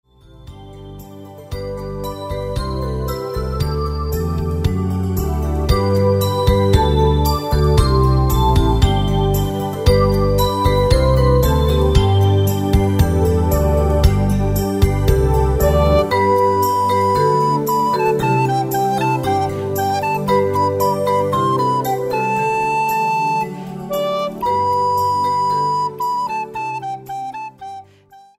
Ein Weihnachts-Minimusical